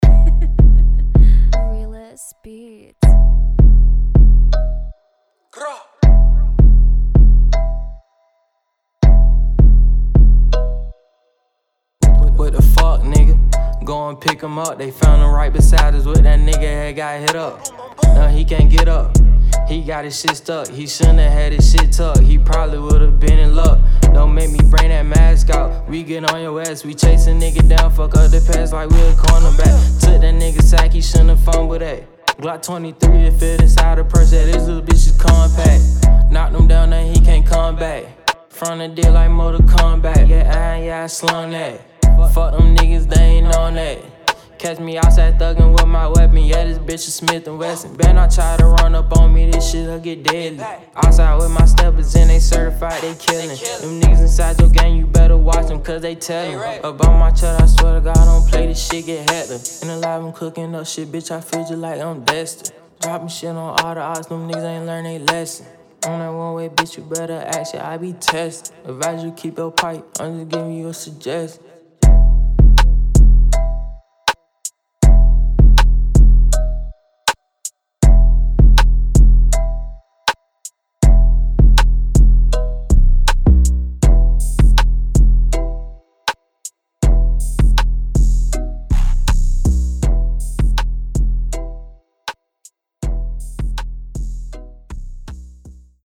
Studio recording client - Richmond